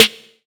MZ Snare [Neptune].wav